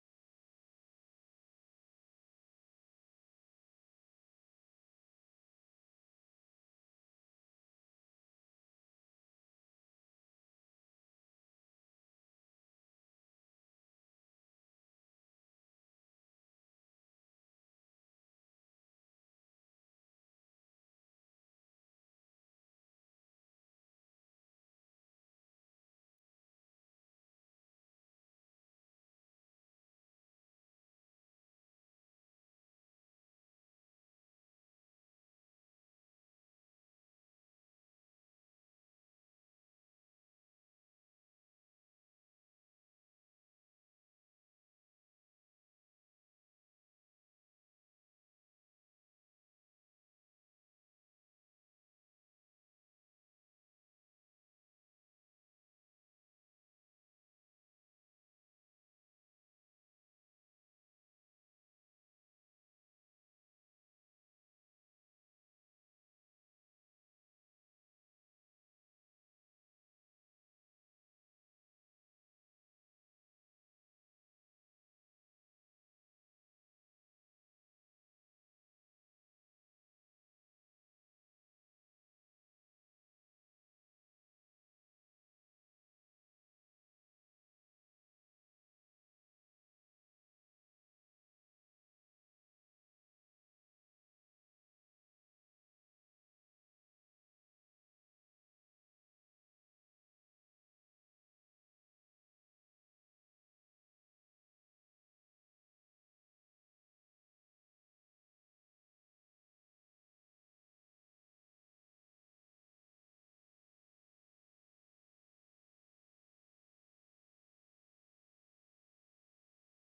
The URL has been copied to your clipboard 페이스북으로 공유하기 트위터로 공유하기 No media source currently available 0:00 0:59:58 0:00 생방송 여기는 워싱턴입니다 생방송 여기는 워싱턴입니다 공유 생방송 여기는 워싱턴입니다 share 세계 뉴스와 함께 미국의 모든 것을 소개하는 '생방송 여기는 워싱턴입니다', 저녁 방송입니다.